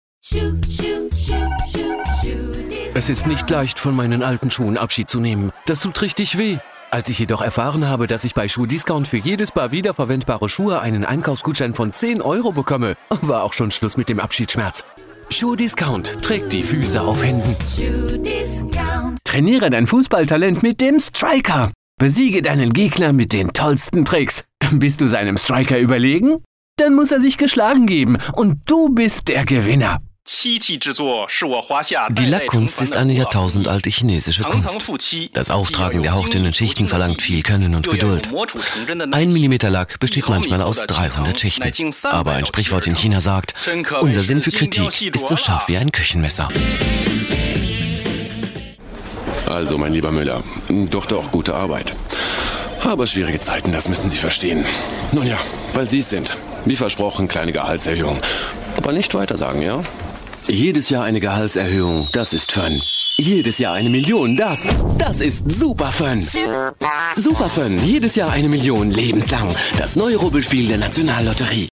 Sprechprobe: Werbung (Muttersprache):
Professional Fulltime Versatile Native German and French Voice Over Talent (bilingual by birth) I do- French & German recordings as a native but also offer accented readings in English Age Range 35 - 45 • Young Adult Male • Middle Age Male Voice Description Neutral Friendly Cool Corporate Upscale Sophisticated Cartoonish Fresh Hard Sell My Services • Commercials • Promos • IVR, voicemail, phone systems, and on-hold messages • Training, business presentations, sales, and web sites • Audiobooks • Videogames • Documentaries • TV shows and movies • Movie and game trailers • Podcasts